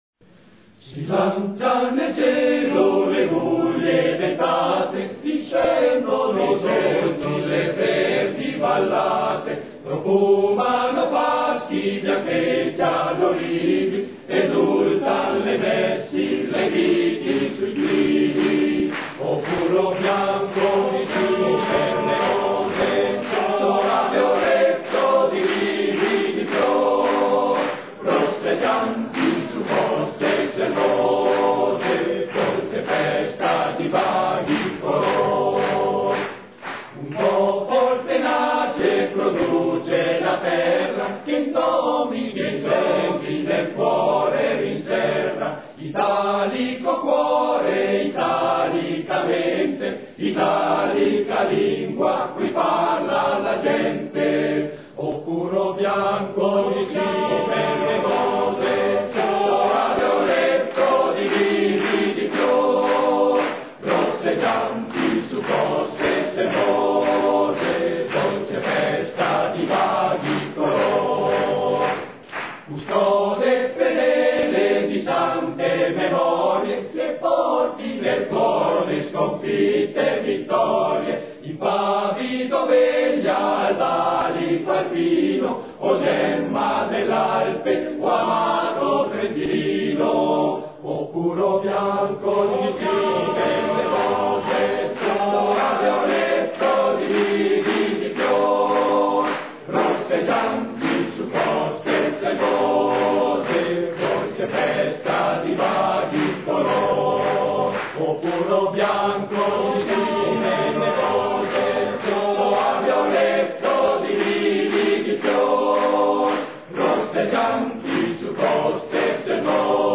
Documento della cerimonia di intitolazione del centro mariapoli di Cadine a Chiara Lubich - 24 gennaio 2009
Coro Dolomiti: “La montanara” – “